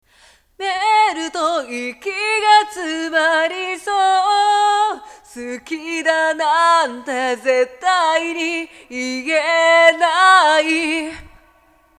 リバーブのかかっているファイルの音量を極力下げ（左側のツマミで－27dbまで下げています）、アカペラのファイルに被せるようにします。
♪かぶせたファイル
これで歌声も分かりやすくややエフェクトがかかった感じになりました。